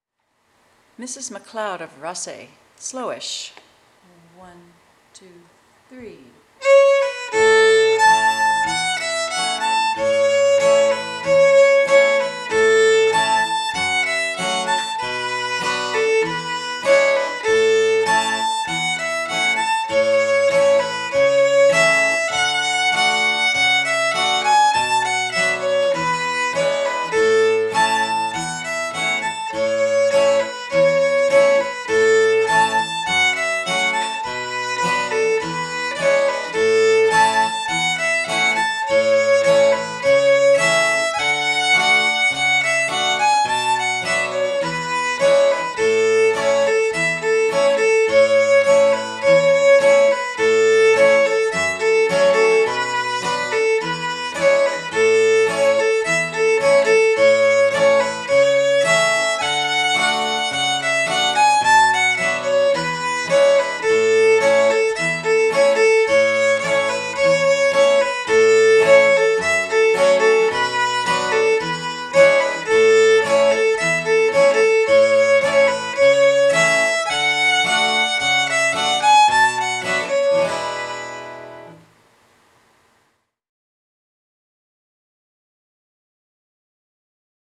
guitar accompaniment
Mrs MacLeod, Slower
MrsMacLeodSlowA.aif